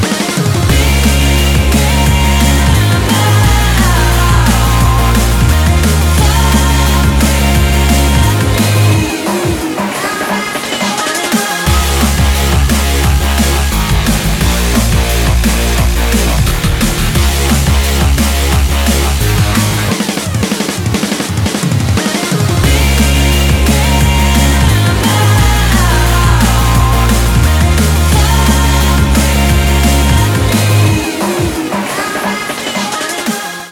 заводные
энергичные
drum&bass
Заводная, энергичная драм мелодия из новой гоночной игры